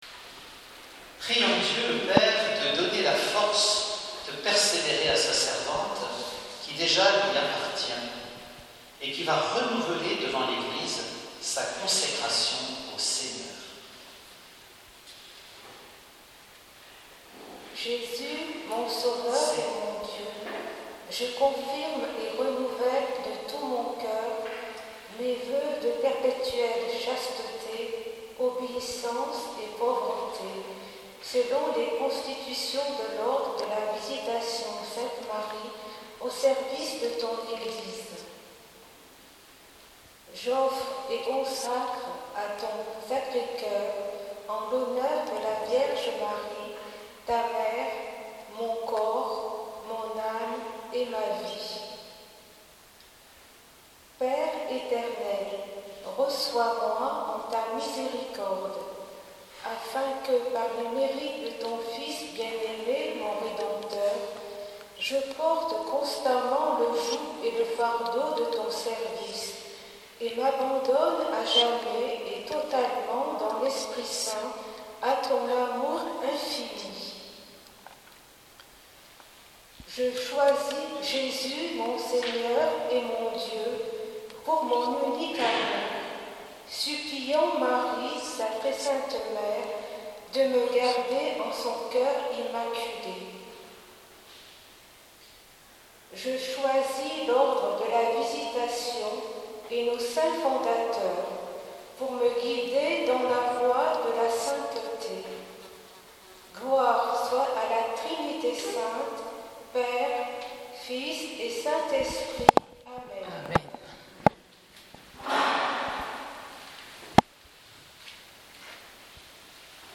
Le renouvellement des voeux :